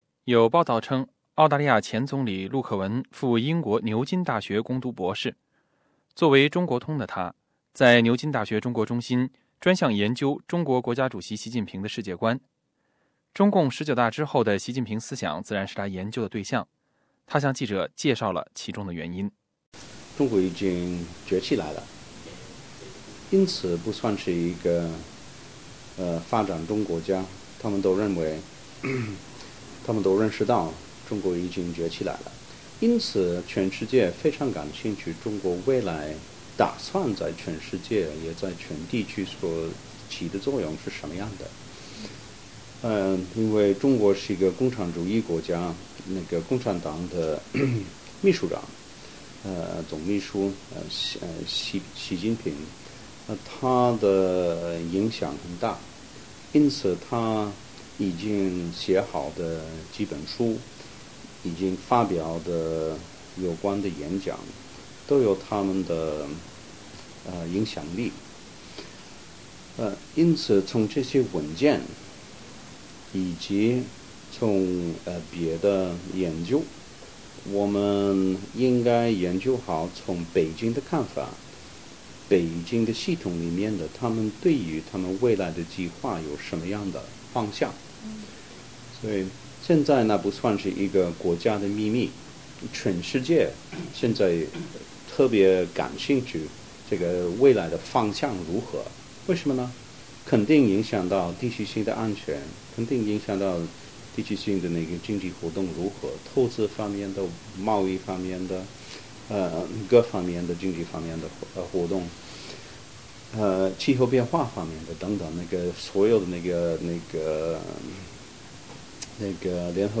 活动当天，陆克文接受了中文媒体的采访。他介绍了自己近期在纽约的工作，并且就包括外交白皮书、外国政治献金和中国影响力渗透澳大利亚政治等一系列目前的热点话题回答了记者的提问。